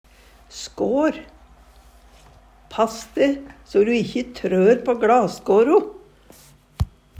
skår - Numedalsmål (en-US)